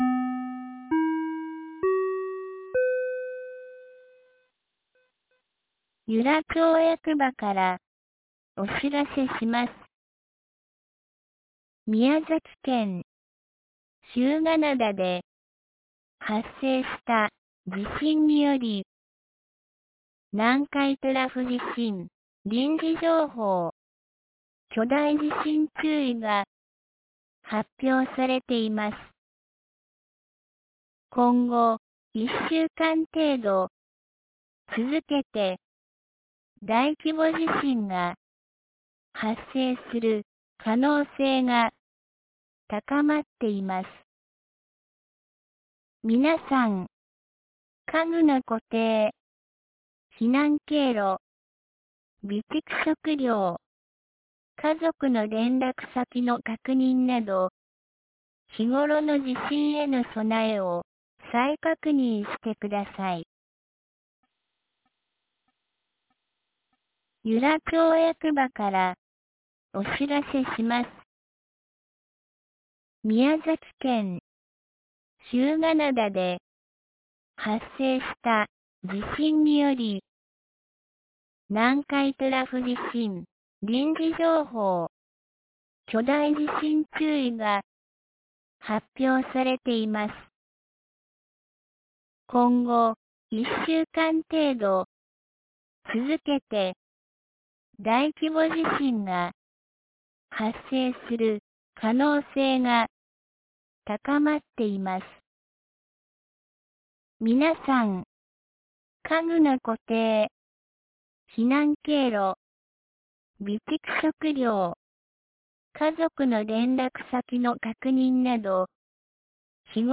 2024年08月09日 16時02分に、由良町から全地区へ放送がありました。